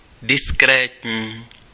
Ääntäminen
IPA : /dɪsˈkɹiːt/